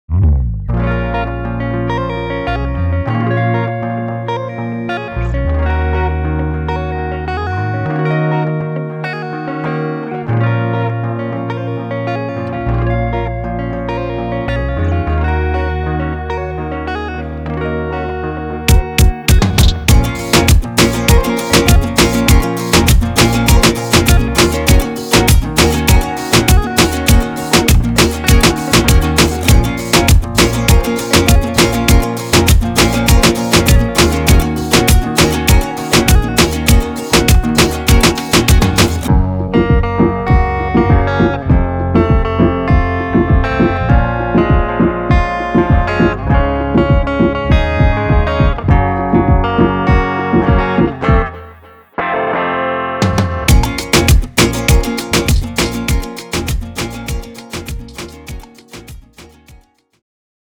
This pack was performed and recorded remotely by Tegucigalpa
guitar and piano
gon bops